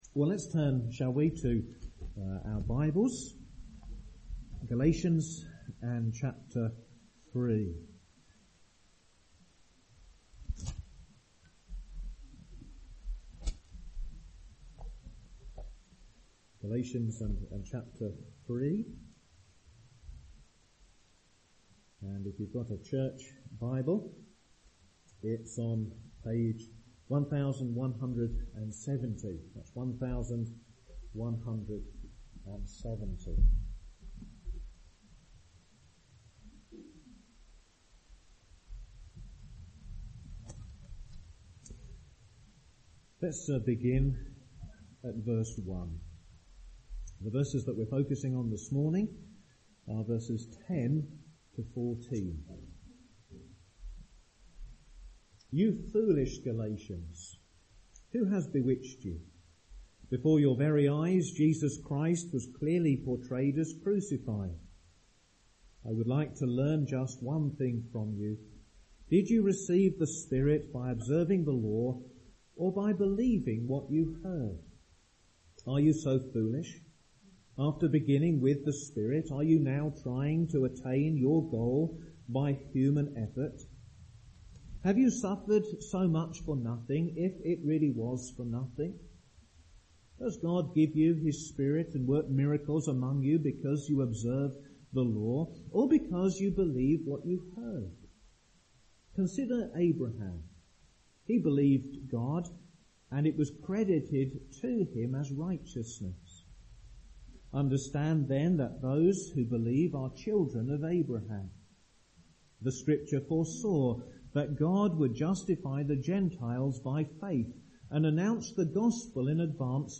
Sermon outline